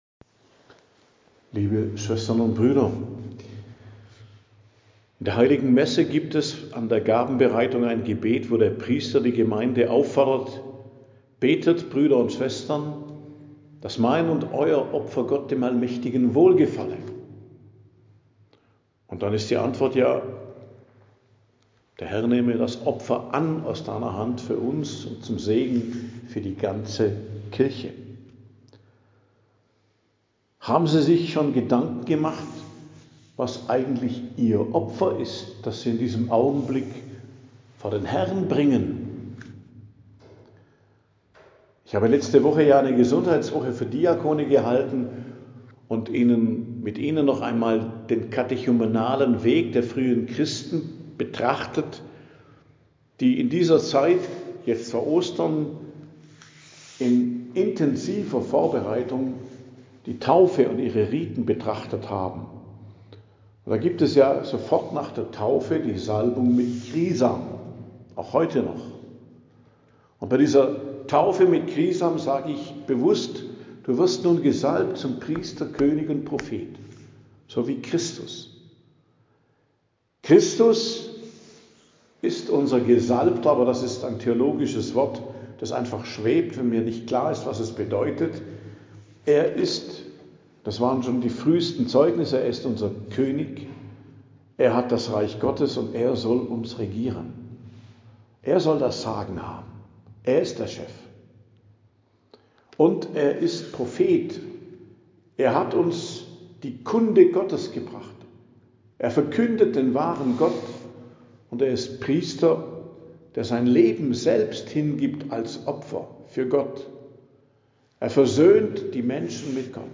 Predigt am Dienstag der 3. Woche der Fastenzeit, 10.03.2026